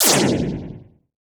Power Laser Guns Demo
LaserGun_68.wav